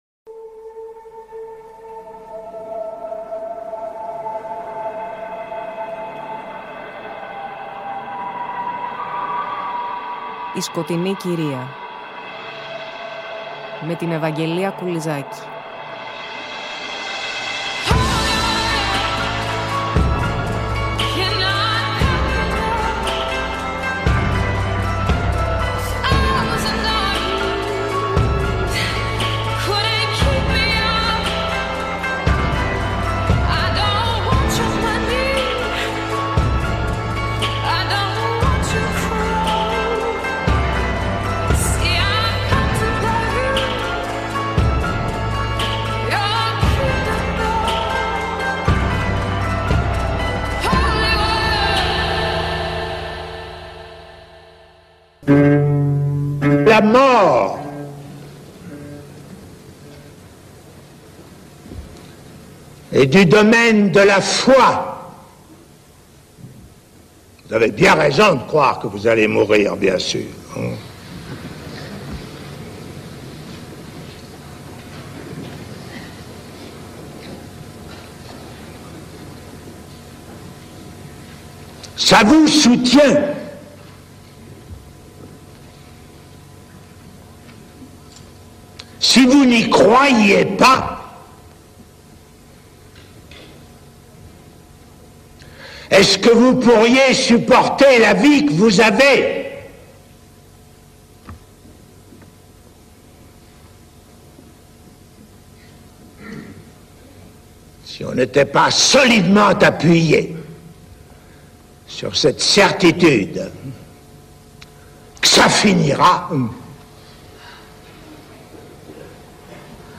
Πλαισιώνουμε μουσικά (και) με αποσπάσματα από το έργο του Luciano Berio “Epifanie” .